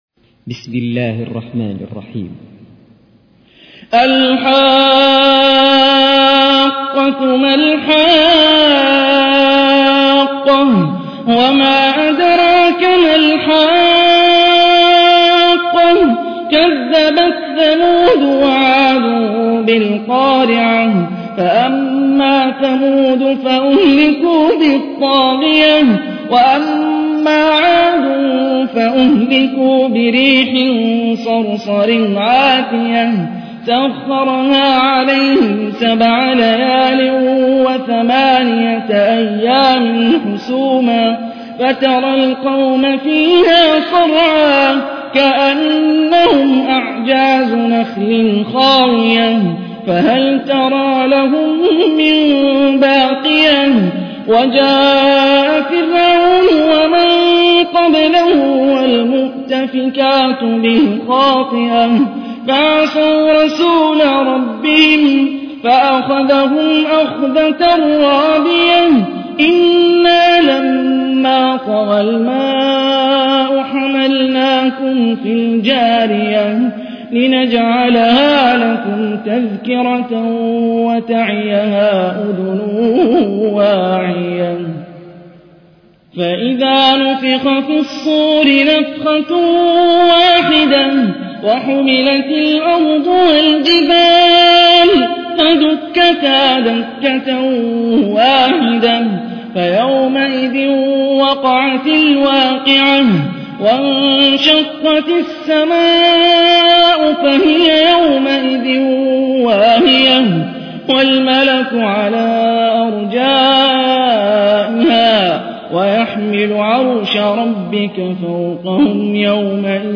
تحميل : 69. سورة الحاقة / القارئ هاني الرفاعي / القرآن الكريم / موقع يا حسين